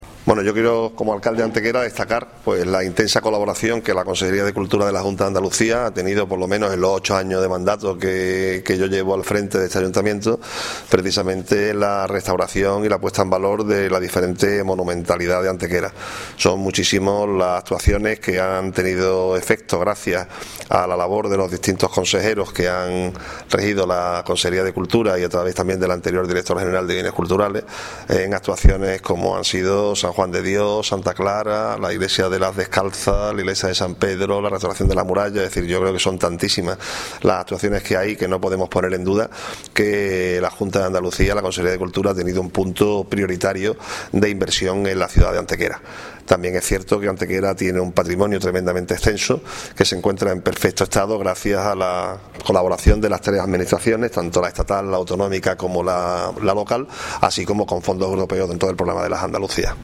Audio: alcalde | mp3